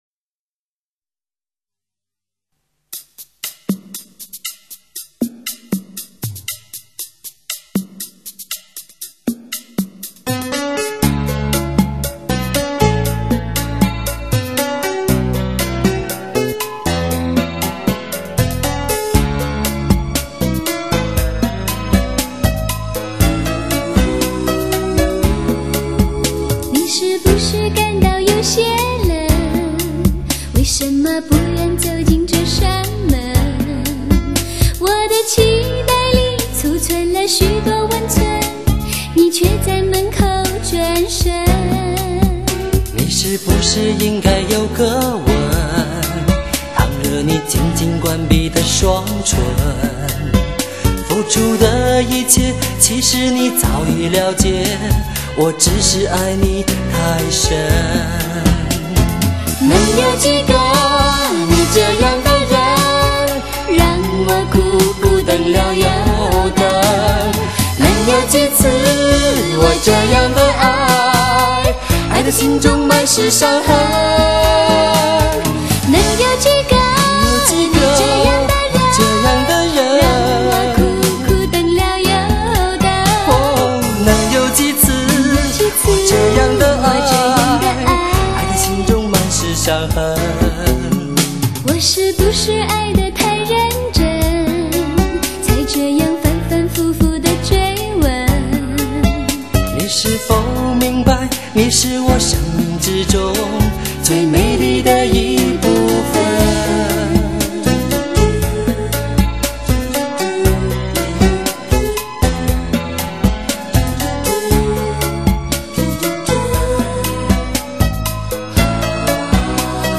一种歌声，关于爱情，从相爱的那一天我们开始聆听，两个人的对唱，